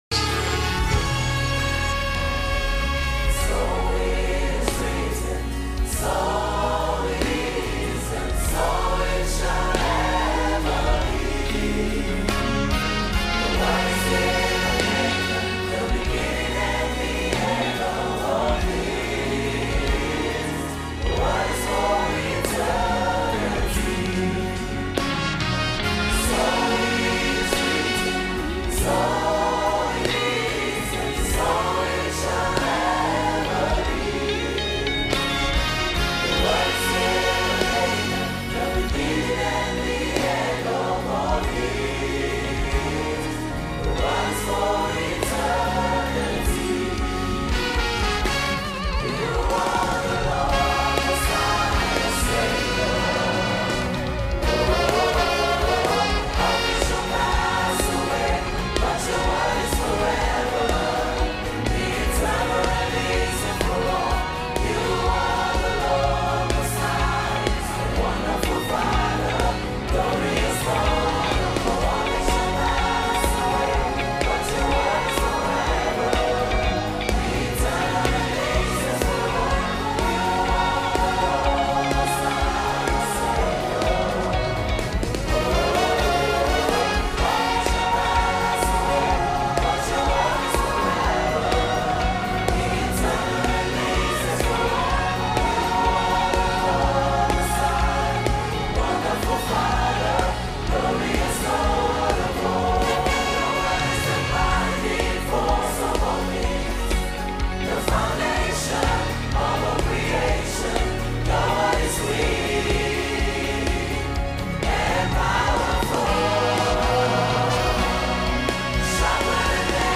MORE MEDLEYS